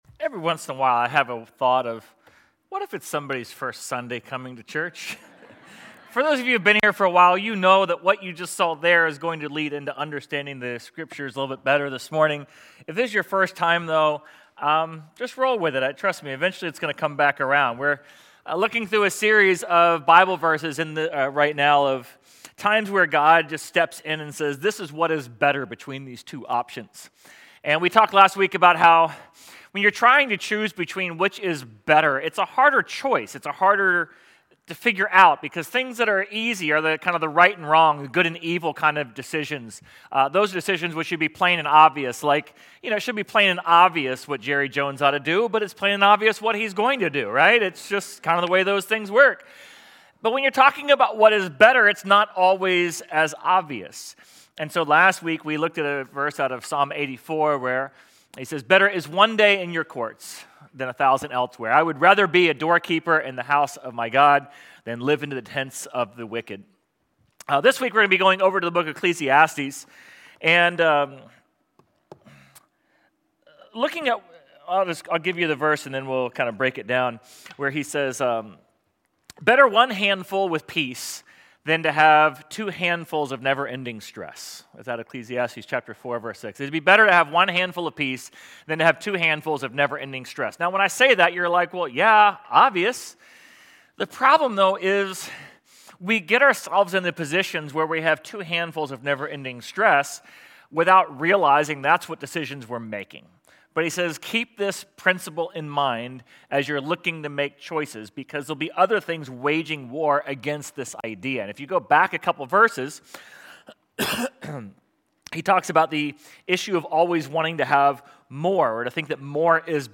Sermon_8.31.25.mp3